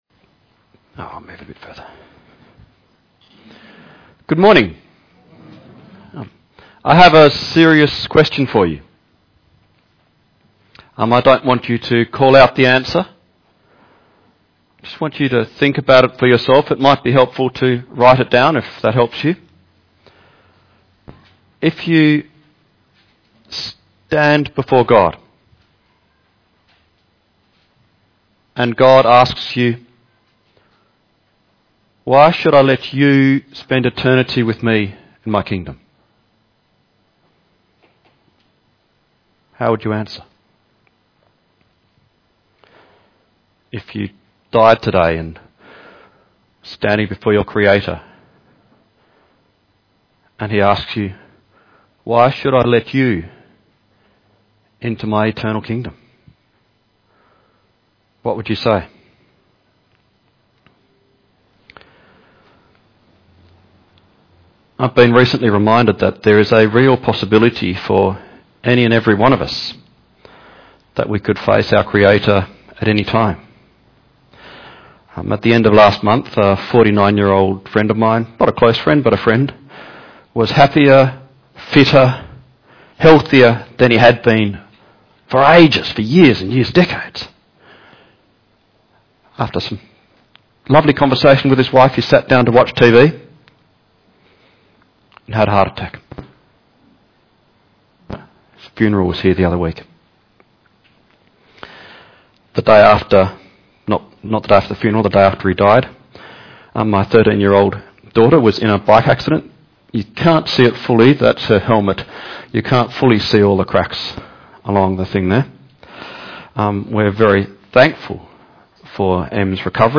Mark 10:13-31 Service Type: Sunday AM Bible Text